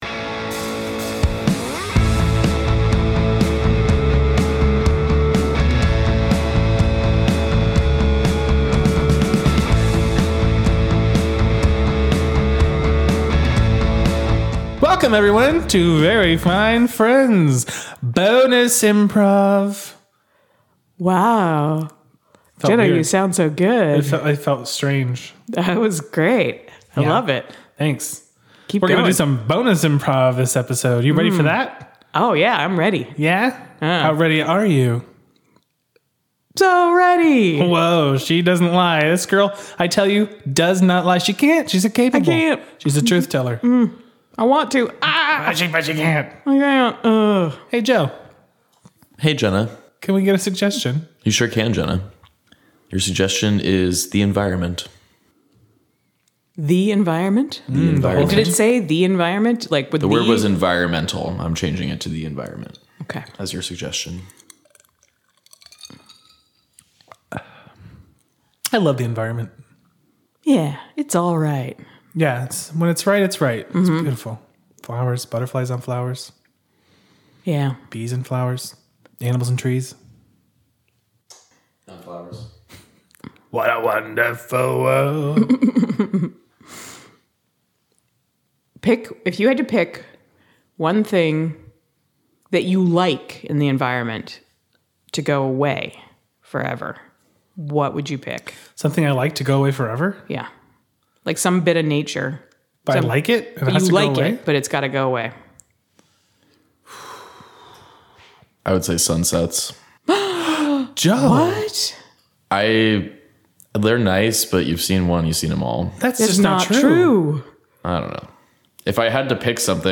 Bonus IMPROV - I'd Had a Few Harvey Wallbangers